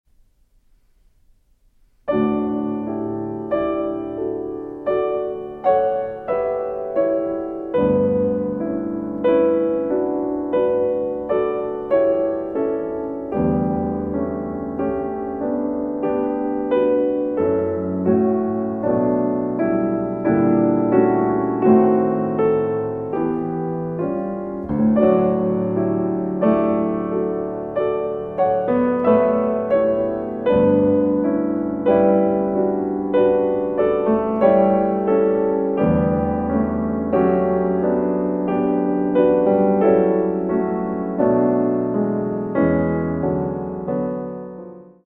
Quasi adagio cantabile ed espressivo (2:48)